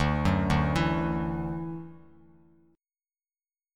Dadd9 chord